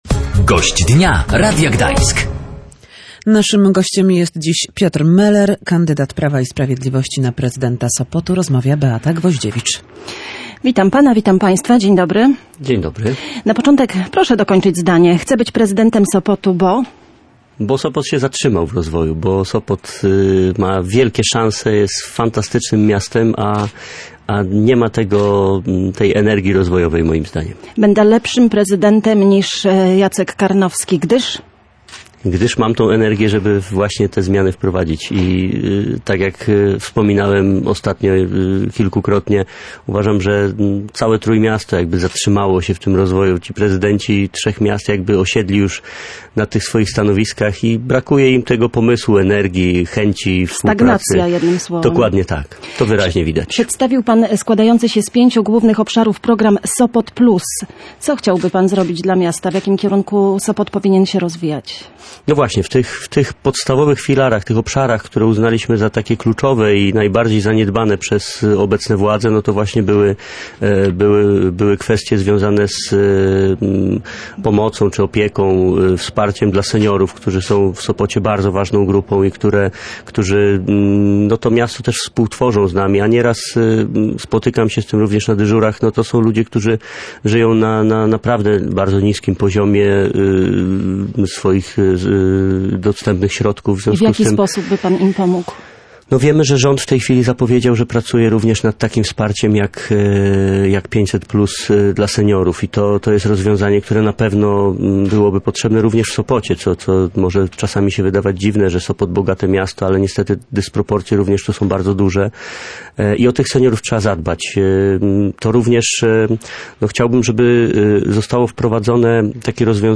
– Sopot się zatrzymał w rozwoju. Ma wielkie szanse, jest fantastycznym miastem, a nie ma energii rozwojowej – mówił Gość Dnia Radia Gdańsk Piotr Meler, sopocki radny PiS i kandydat na prezydenta Sopotu. Piotr Meler przedstawił niedawno program dla miasta o nazwie „Sopot Plus”. Jego zdaniem trzeba stawiać na pomoc i opiekę społeczną, szczególnie dla seniorów.